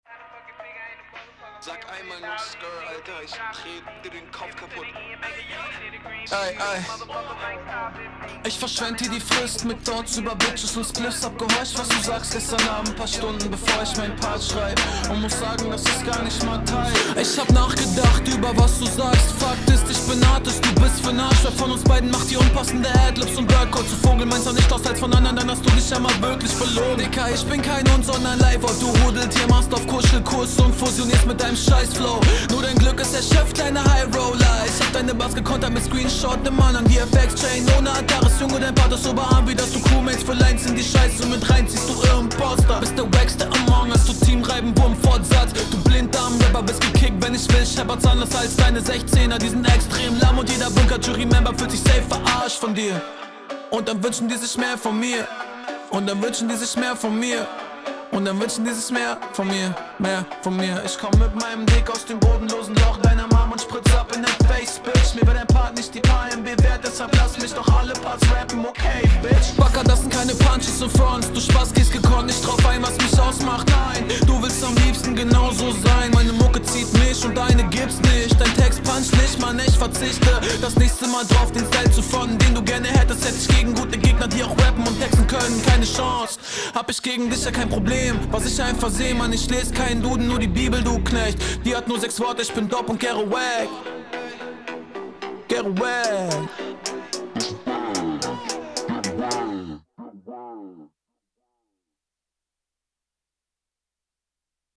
Der Beat liegt dir; das verzichten aufs Autotune zahlt sich definitiv aus.